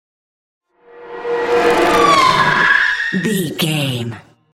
Horror whoosh large
Sound Effects
Atonal
scary
ominous
haunting
eerie